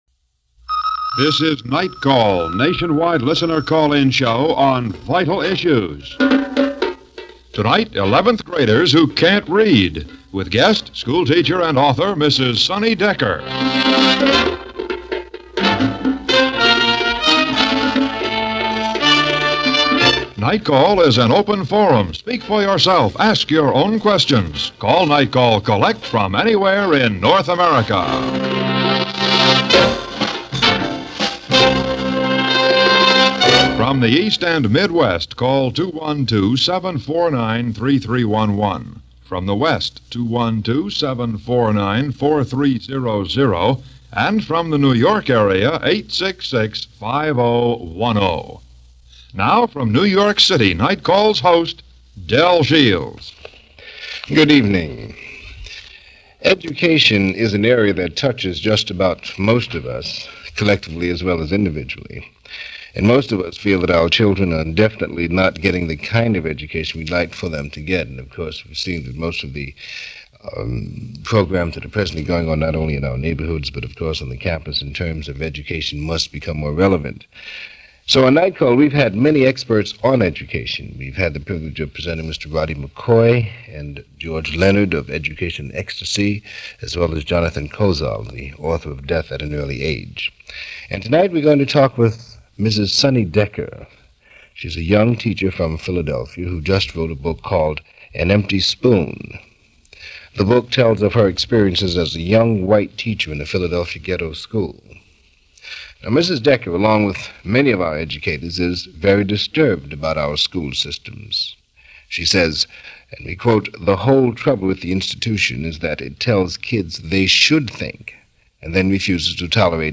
Some factors that contributed hadn’t fully been arrived at yet in 1969, the date this discussion program took place.